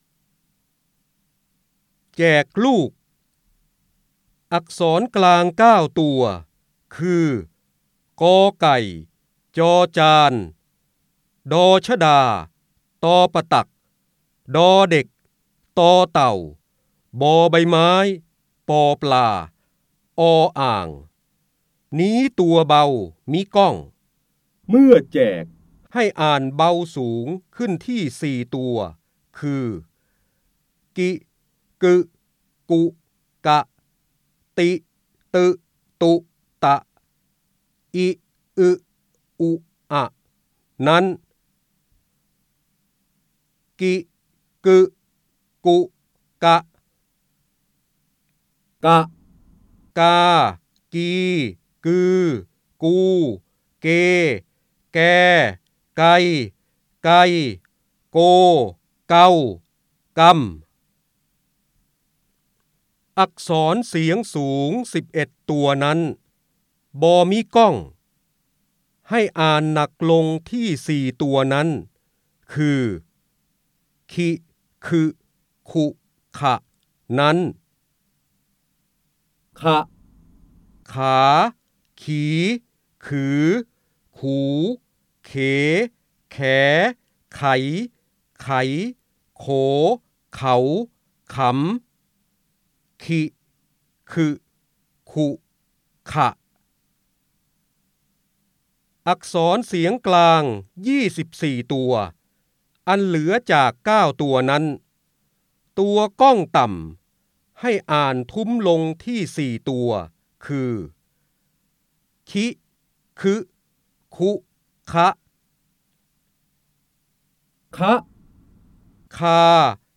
เสียงบรรยายจากหนังสือ จินดามณี (พระโหราธิบดี) แจกลูก
คำสำคัญ : ร้อยกรอง, พระเจ้าบรมโกศ, พระโหราธิบดี, การอ่านออกเสียง, ร้อยแก้ว, จินดามณี
ลักษณะของสื่อ :   คลิปการเรียนรู้, คลิปเสียง